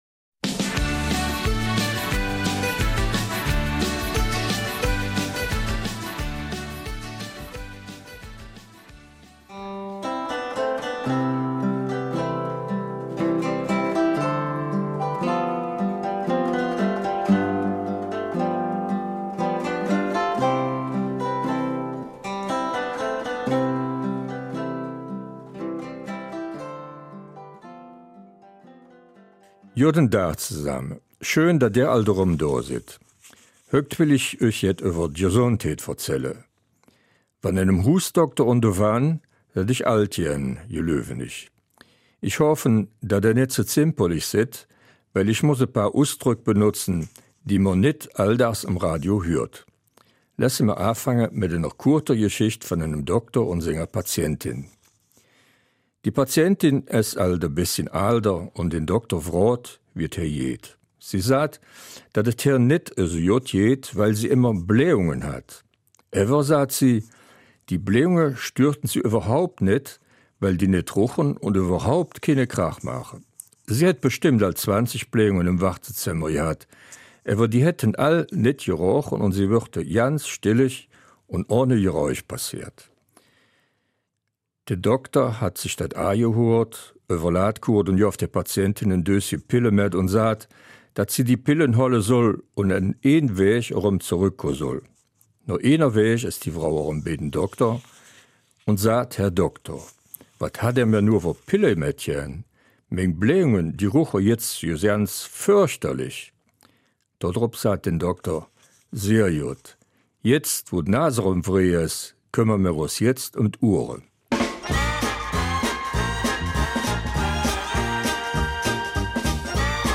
Eifeler Mundart: ''Ich glaube, ich werde alt''